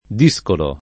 discolo [ d &S kolo ]